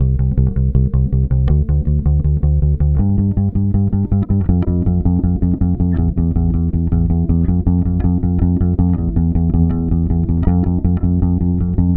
Track 16 - Bass 02.wav